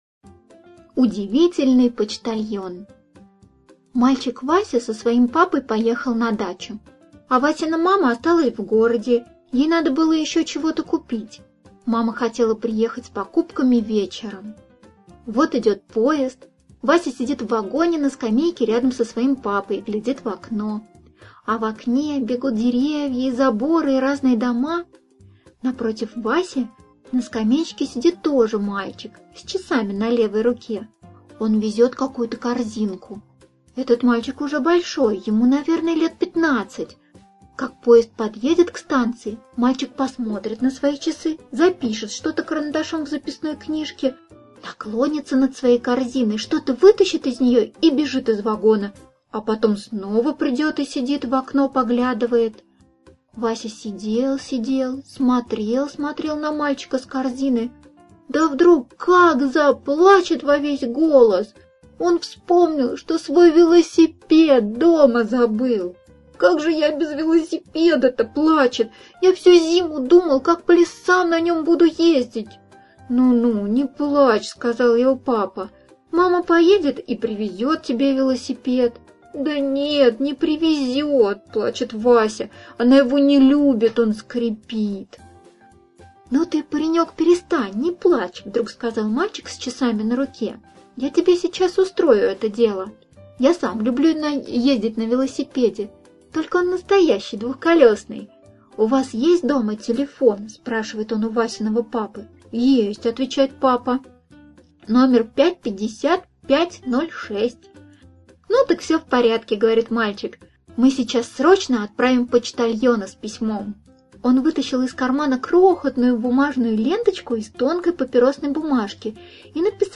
Аудиорассказ «Удивительный почтальон»